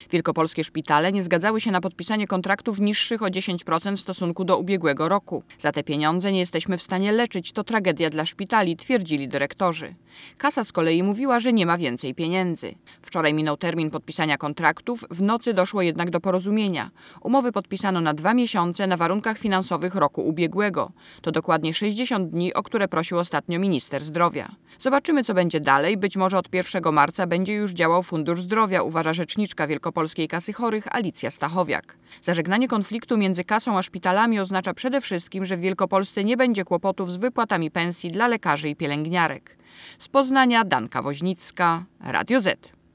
(RadioZet) Źródło: (RadioZet) Posłuchaj reportera Radia Zet Umów z kasami chorych nie podpisało jeszcze wiele szpitali w całym kraju.